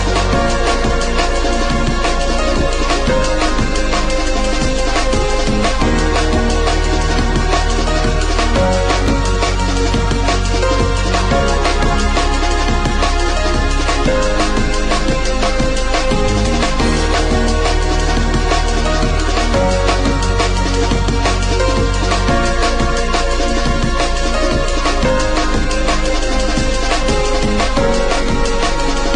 TOP >Vinyl >Drum & Bass / Jungle
TOP > Deep / Liquid